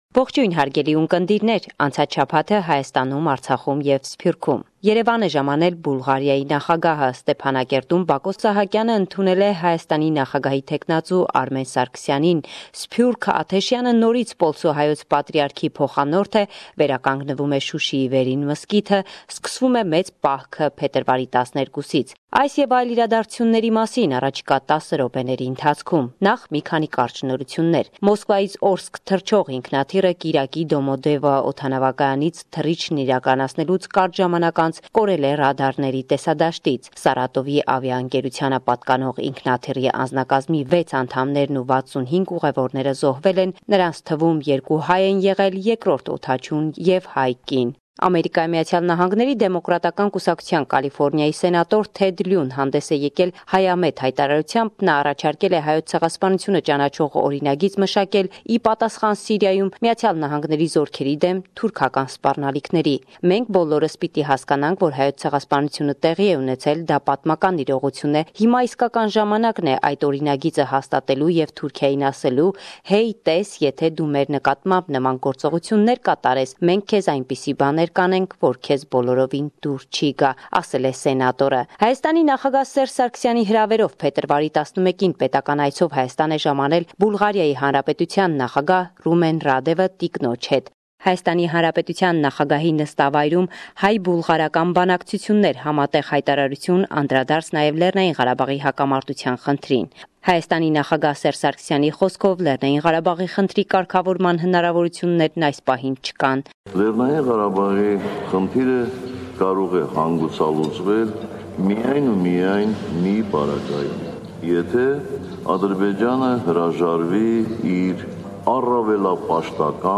Վերջին Լուրերը – 13 Փետրուար, 2018